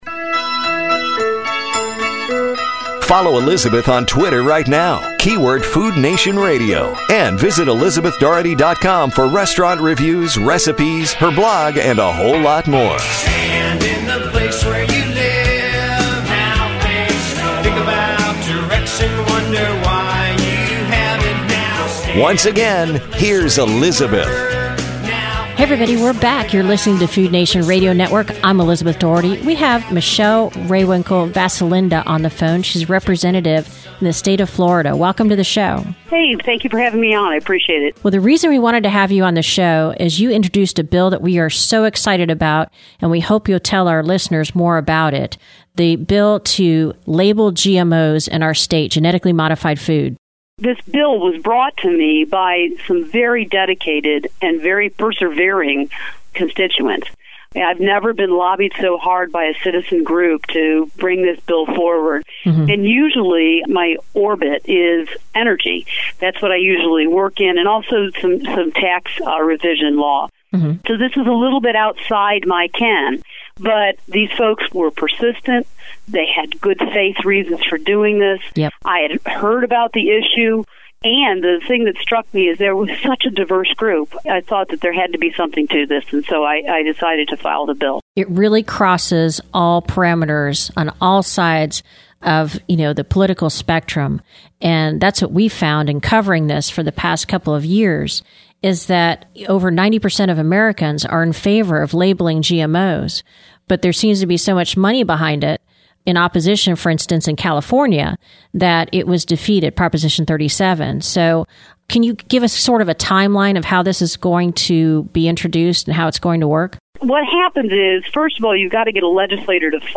In an historic move, Representative Michelle Rehwinkel Vasilinda has introduced a bill that would require labeling of genetically modified food sold in the State of Florida. She appeared on Food Travel USA Network this weekend, where we have broadcast continuous coverage of the food labeling issue.